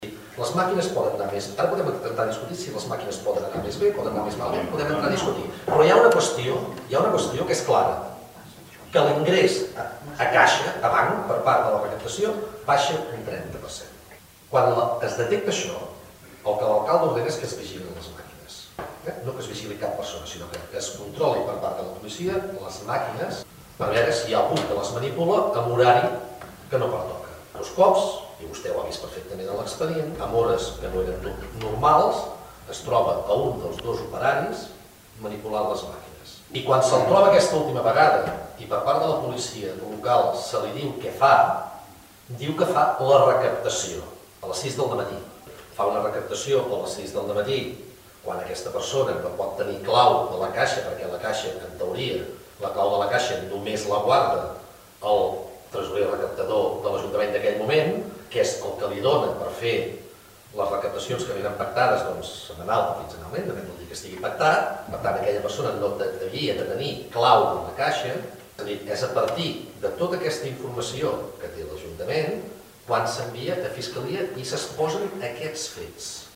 L’alcalde Lluís Sais va defensar la gestió de l’àrea econòmica